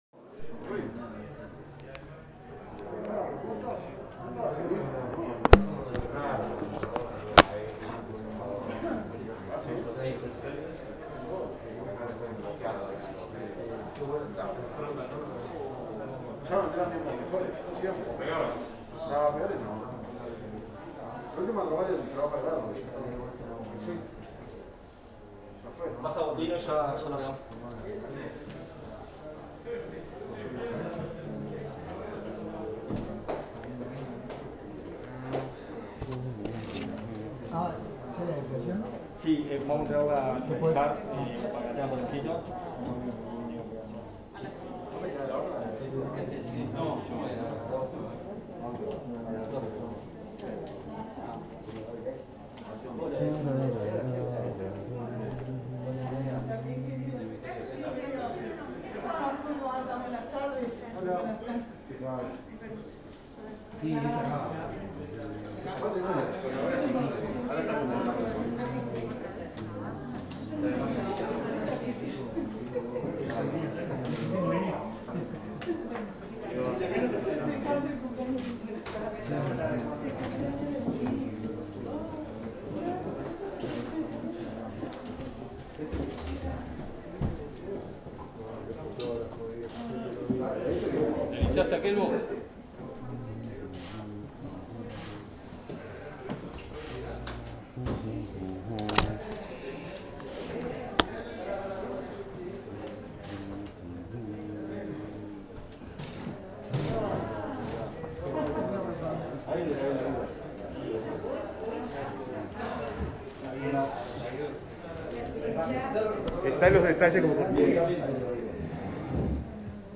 audio_conferencia.mp3